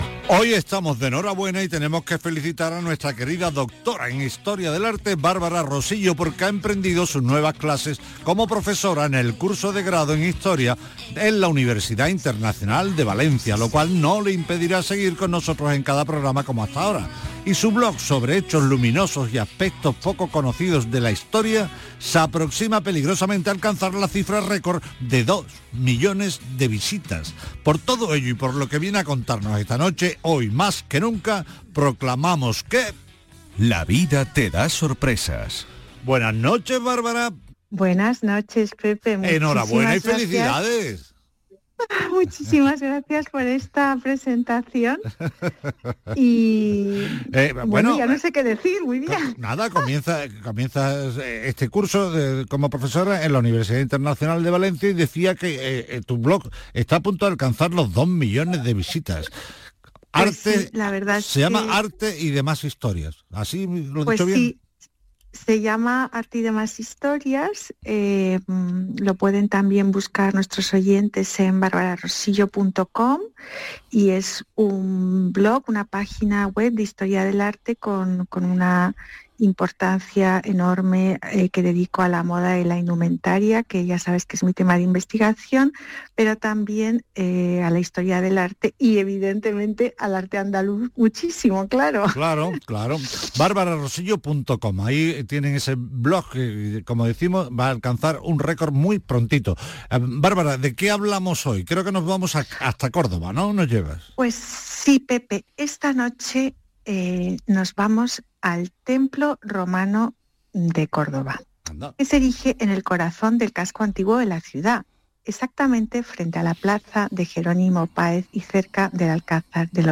Estimados lectores: En este enlace podréis escuchar mi sección «La vida te da sorpresas» en el programa de Radio Andalucía Información, «Patrimonio andaluz» del día 23/03/2025.